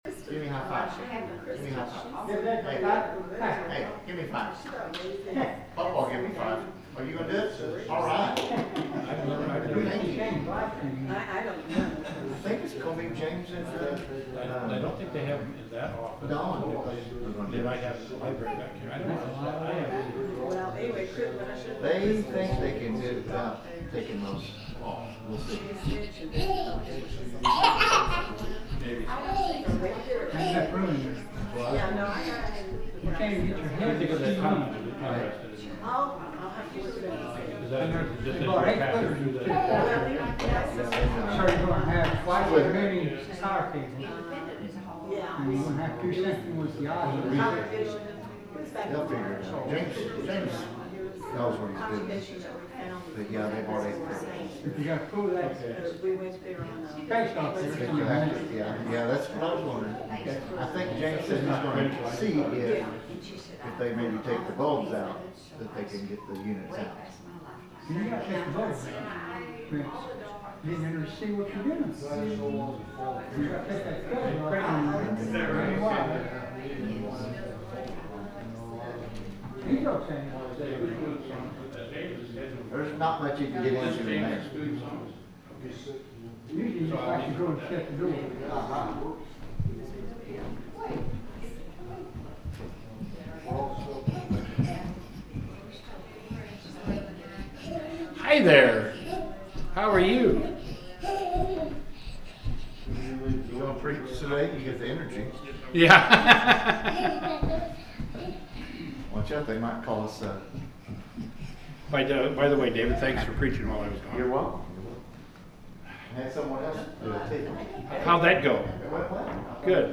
The sermon is from our live stream on 7/27/2025